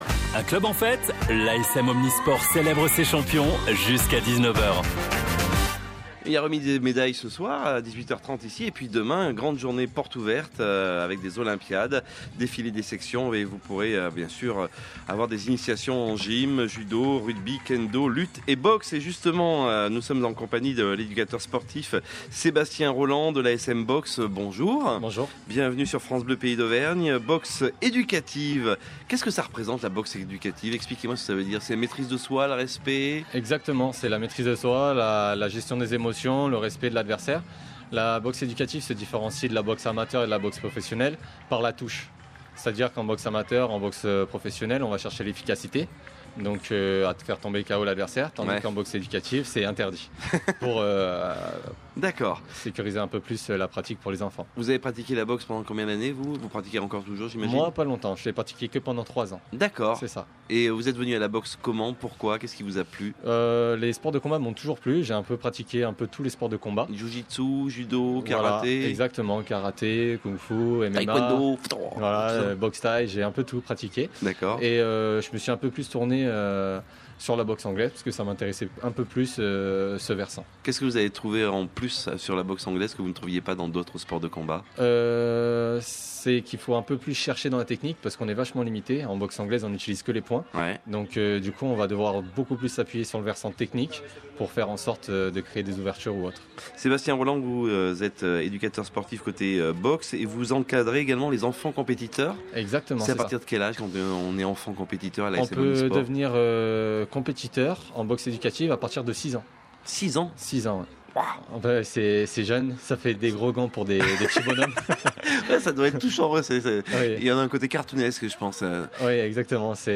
Le vendredi 23 juin dernier, dans le cadre de nos Olympiades ASM, France Bleu Pays d’Auvergne s’est installée à la Gauthière pour diffuser une émission spéciale ASM en direct, de 16h à 19h.
Jusqu’au 28 août, retrouvez les 14 interviews extraites de cette émission spéciale.